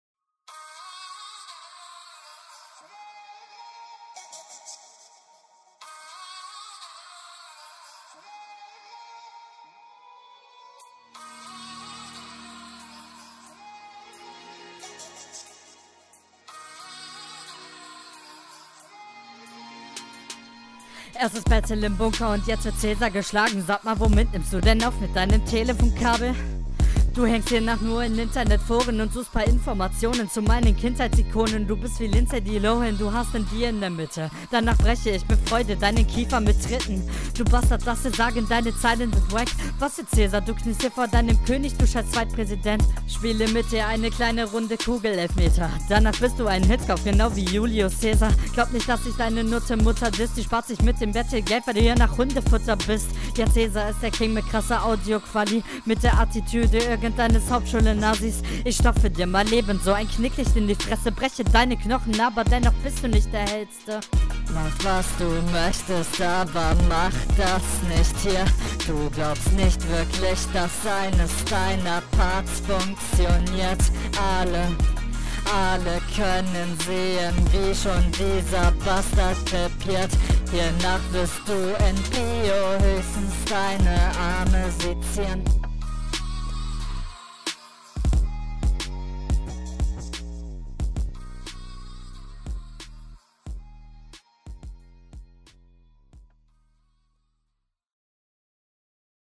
Flow manchmal bisschen holprig und nicht onpoint.